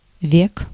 Ääntäminen
IPA: /ˈɑi.kɑ/